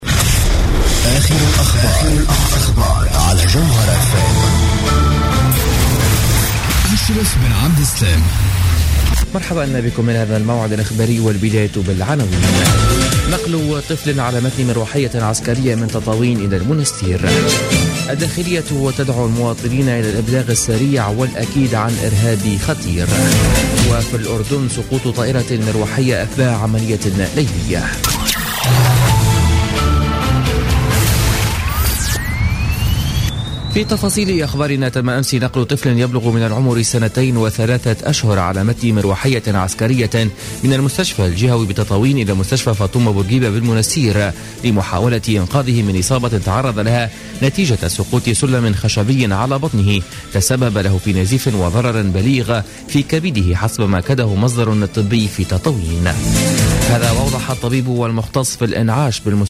Journal Info 00h00 du mercredi 3 février 2016